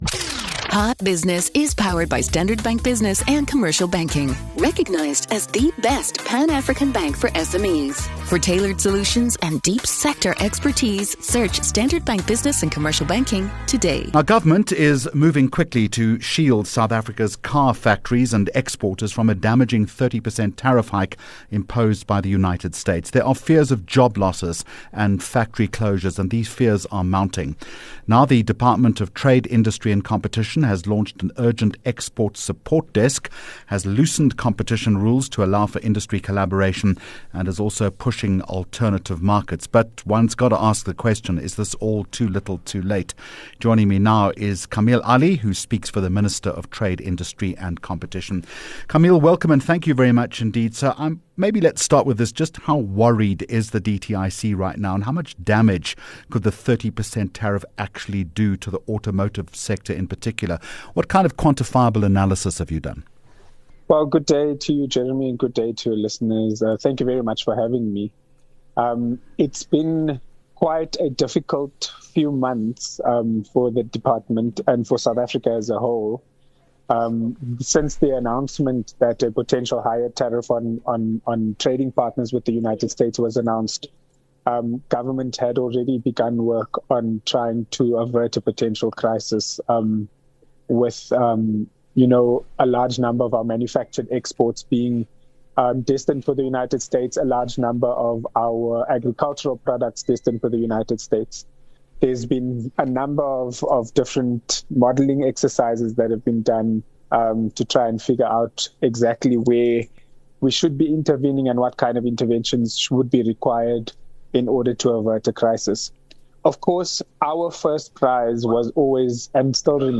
7 Aug Hot Business Interview